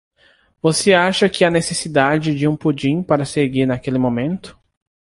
Pronunciado como (IPA)
/puˈd͡ʒĩ/